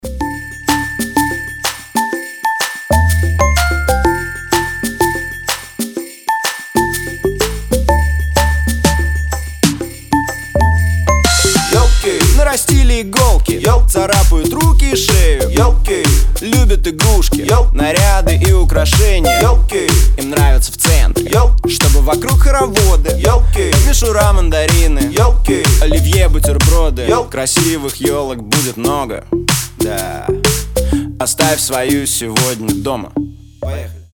• Качество: 320, Stereo
позитивные
мужской вокал
прикольные
праздничные
волшебные
колокольчики
новогодние
Новогодняя хулиганская песня, или хвойный блокбастер)